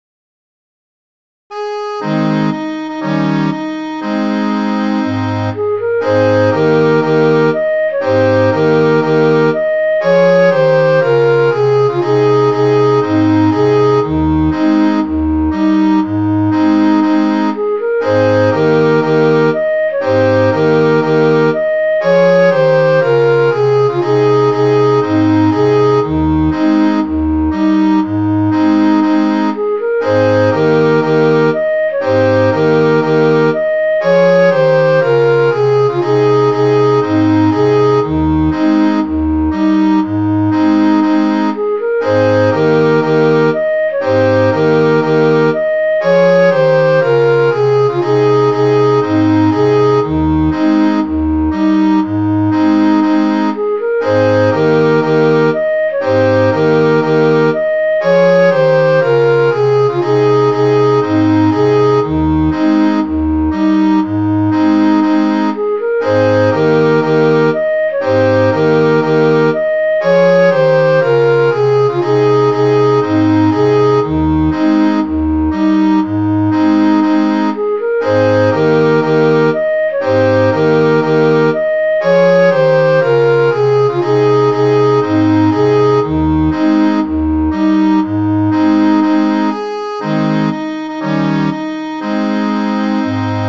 Midi File, Lyrics and Information to The Ox-Eyed Man
This song was usually a capstan shanty , but it was also used for pumping.